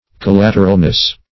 Collateralness \Col*lat"er*al*ness\, n. The state of being collateral.